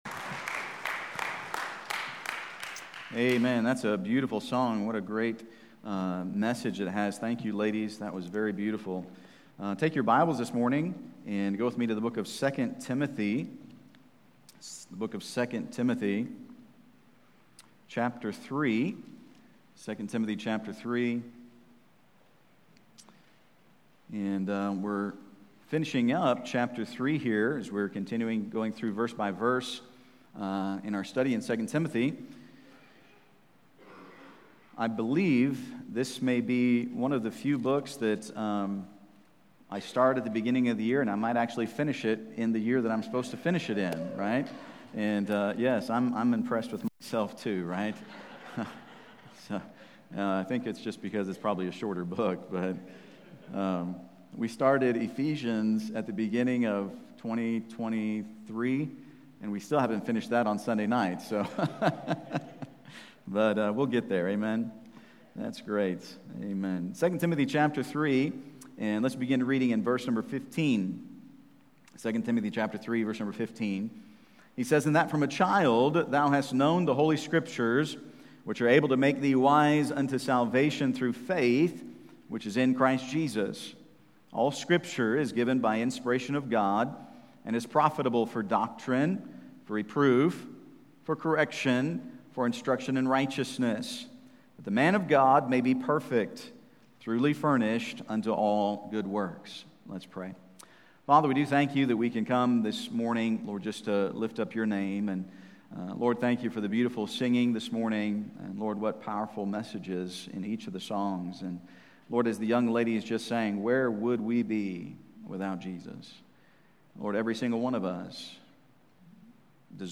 Sermons | First Baptist Church